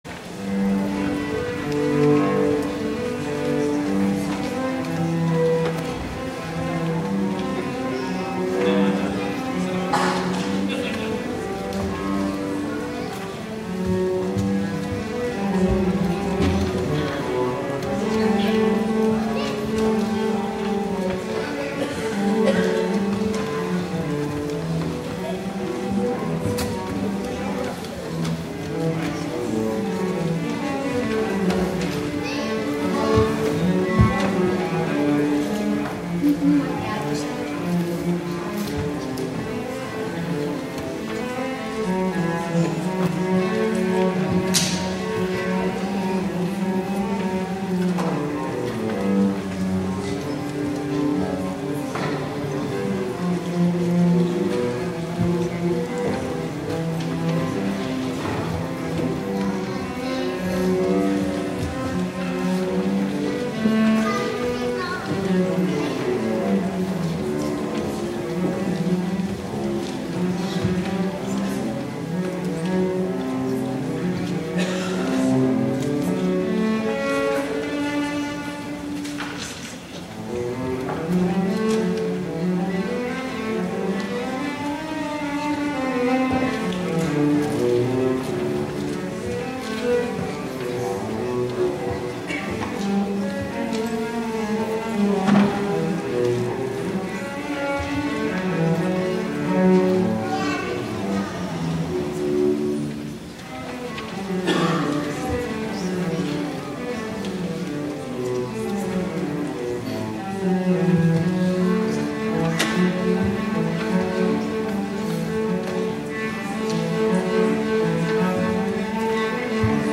THE PRELUDE
Cello